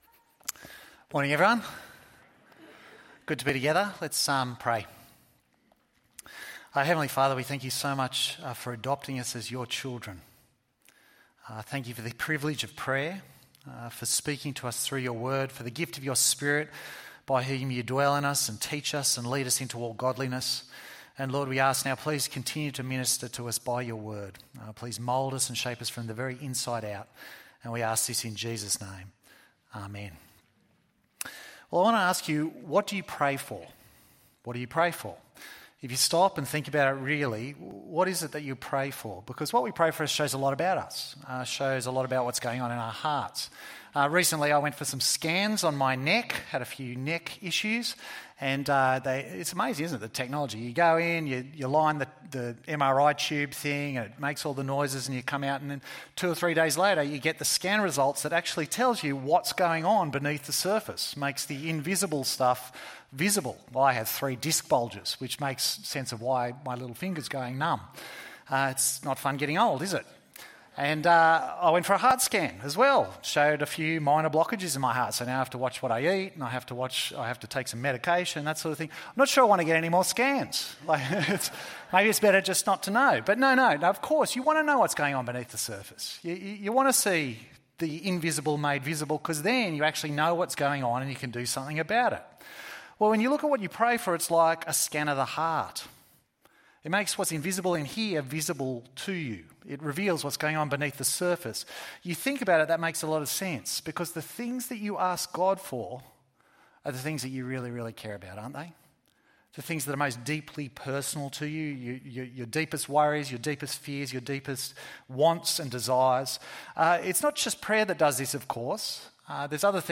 God's Heart ~ EV Church Sermons Podcast